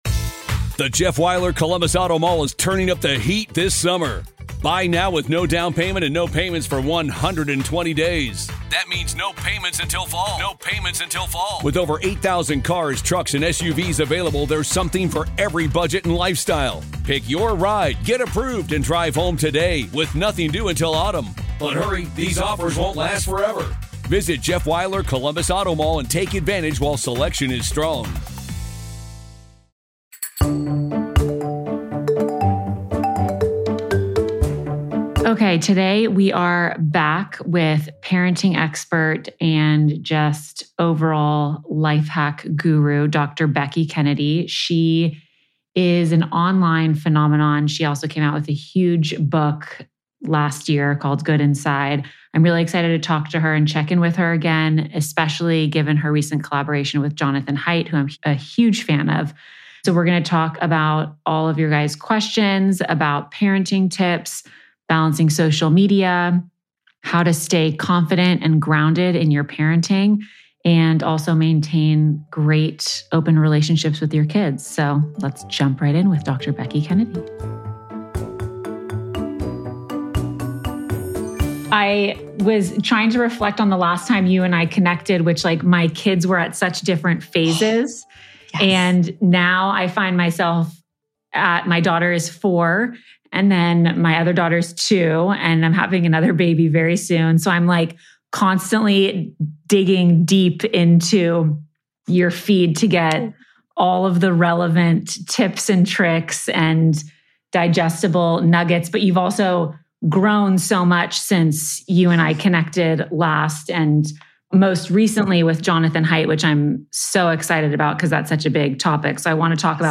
This week on BDA Baby, we sit down with Dr. Becky Kennedy to tackle some of the biggest parenting challenges today.
Tune in for a practical and empowering conversation just in time for the holidays!